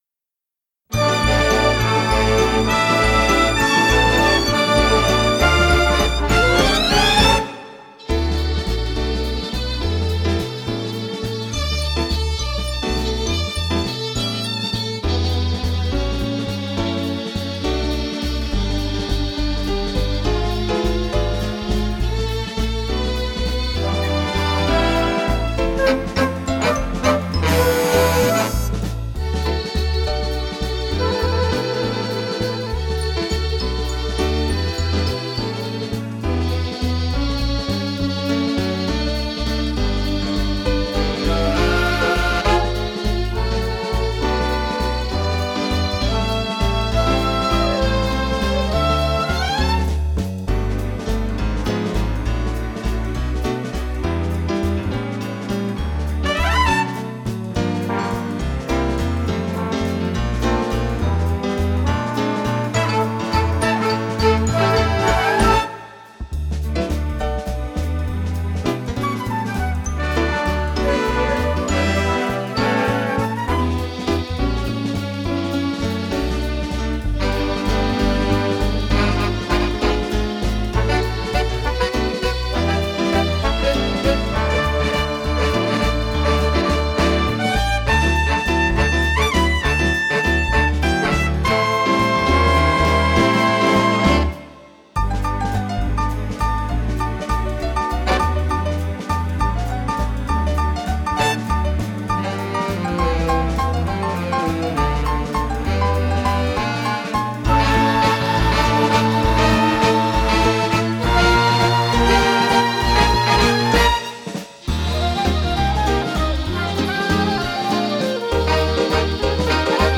Instrumental w/out Backing vocals